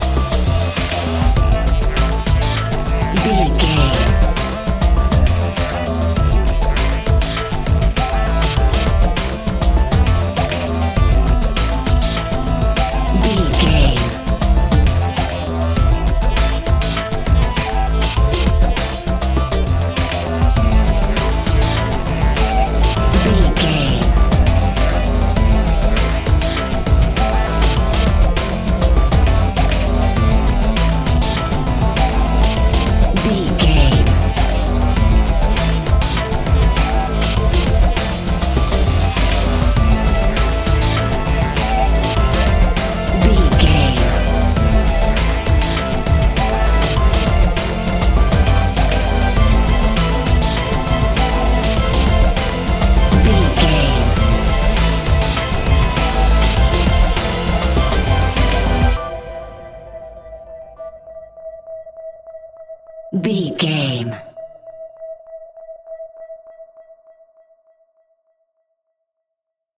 Fast paced
In-crescendo
Aeolian/Minor
synthesiser
orchestral
orchestral hybrid
dubstep
aggressive
energetic
intense
strings
drums
bass
synth effects
wobbles
driving drum beat